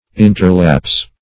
Search Result for " interlapse" : The Collaborative International Dictionary of English v.0.48: Interlapse \In"ter*lapse`\, n. [Pref. inter- + lapse: cf. L. interlabi, interlapsus, to fall, slide, or flow, between.] The lapse or interval of time between two events.